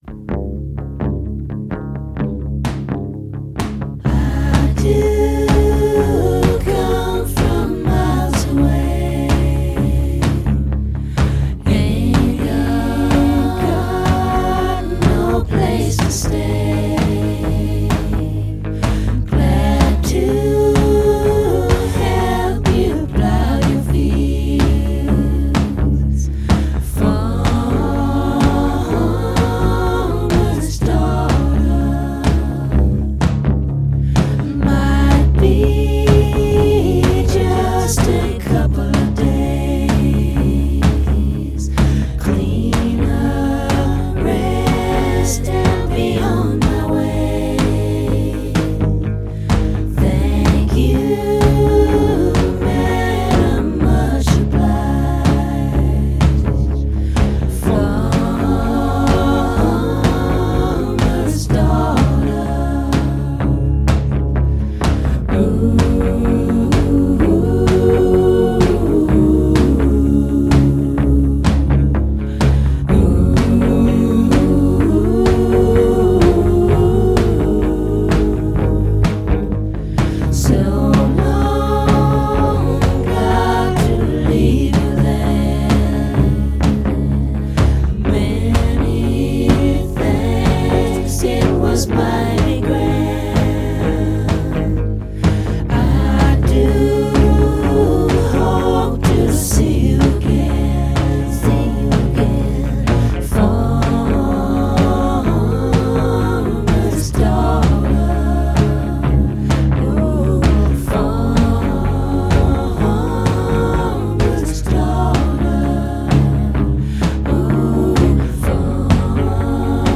is an exquisite showcase for their harmonies
guitar